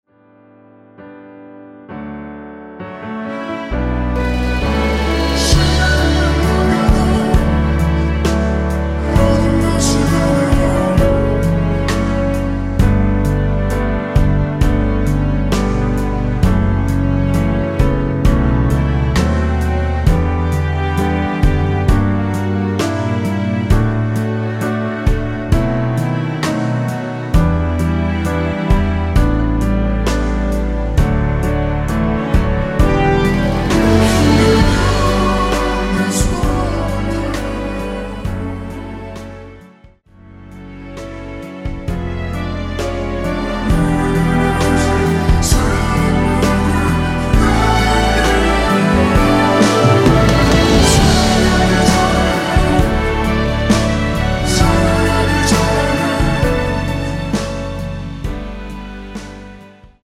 원키에서(-3)내린 코러스 포함된 MR 입니다.(미리듣기 참조)
앞부분30초, 뒷부분30초씩 편집해서 올려 드리고 있습니다.
중간에 음이 끈어지고 다시 나오는 이유는